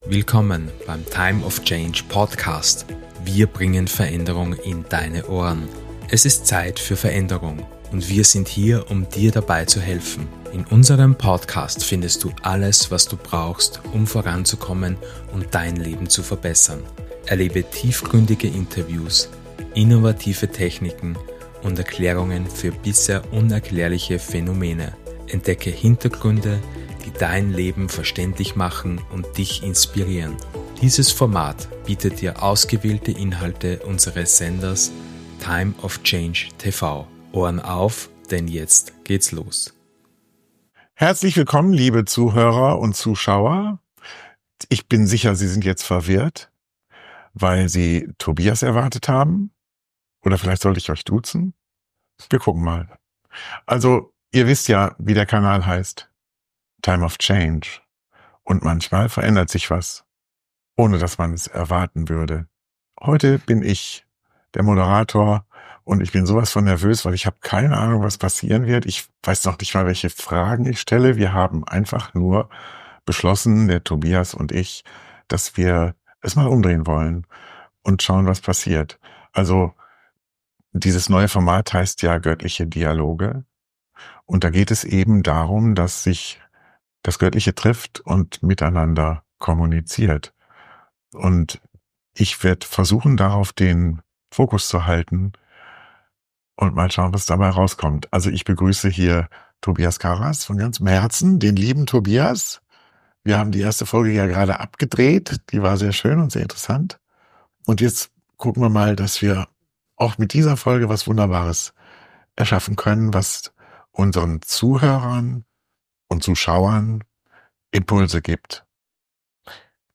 In diesem tiefgründigen Gespräch erwartet Dich eine Reise in die Tiefen des menschlichen Bewusstseins und der spirituellen Entwicklung.